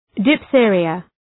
Προφορά
{dıf’ɵıərıə}